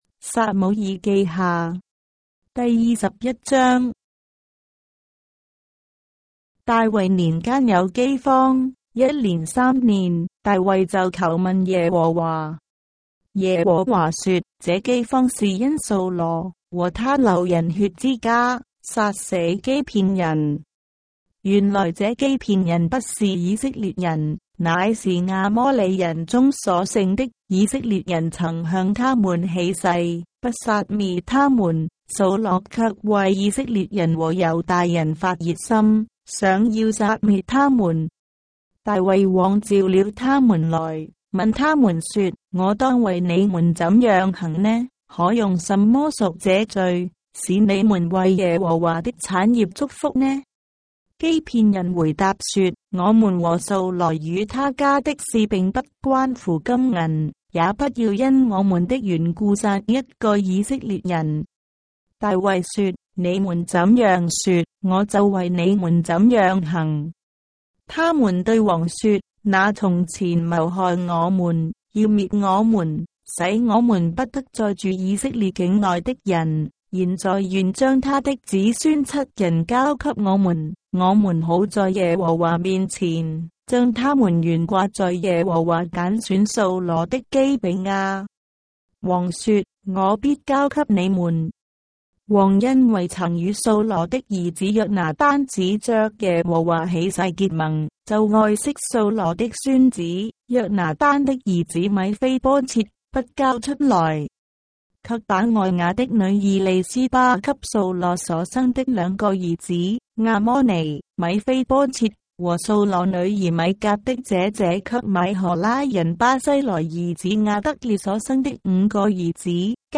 章的聖經在中國的語言，音頻旁白- 2 Samuel, chapter 21 of the Holy Bible in Traditional Chinese